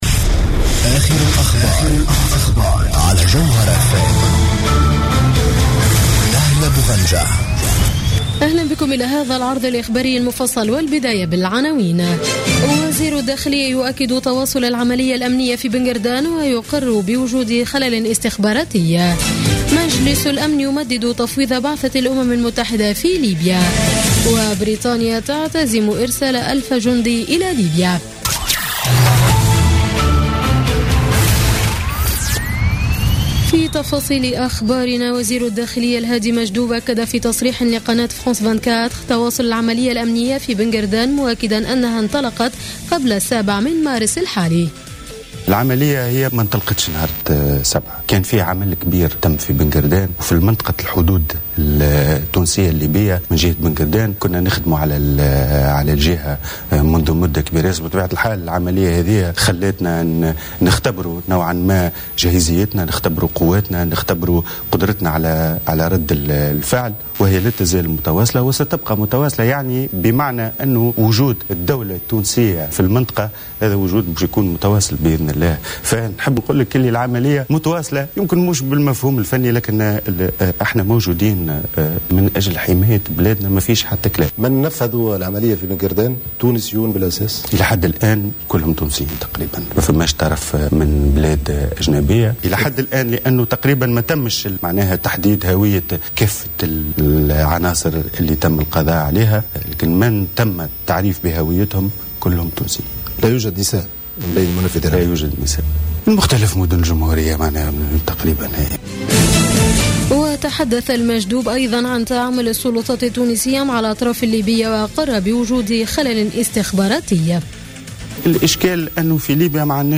نشرة أخبار منتصف الليل ليوم الاربعاء 16 مارس 2016